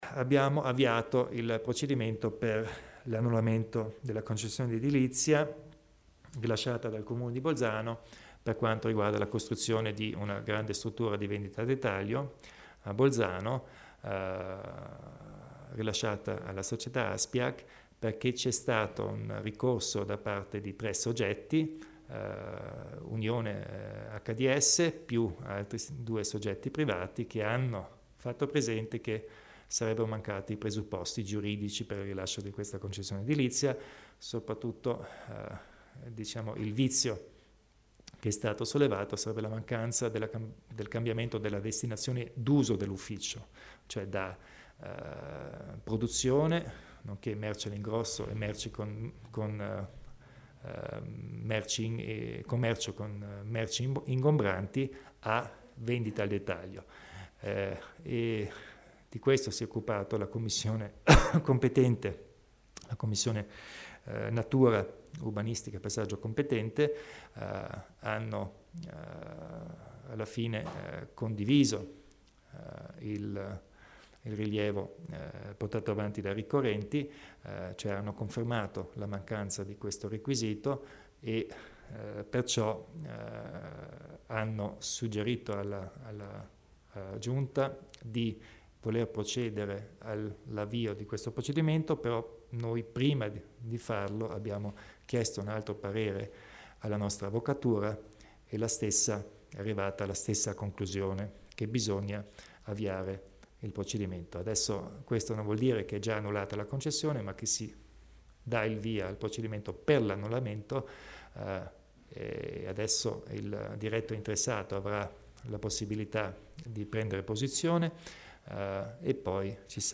Il Presidente della Provincia spiega le motivazioni riguardo l'annullamento della concessione ASPIAG